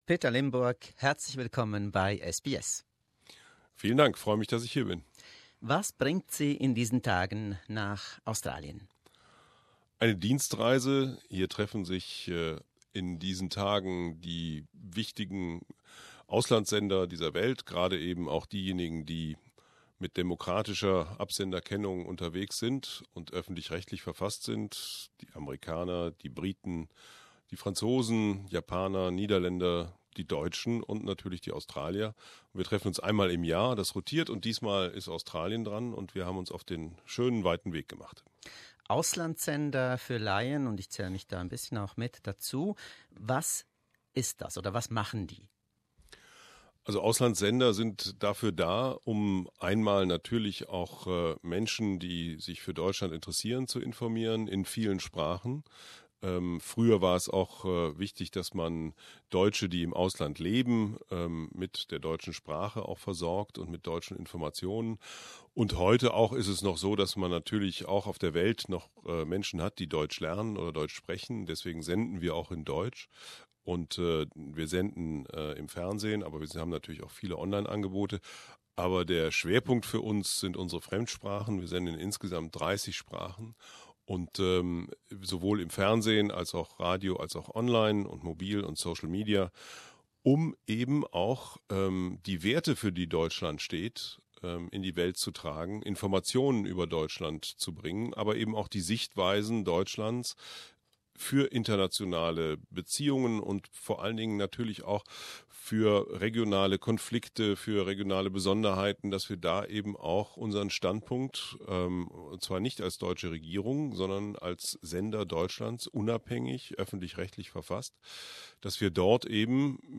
Peter Limbourg in Melbourne - Ein exklusives Interview mit dem Intendanten der DW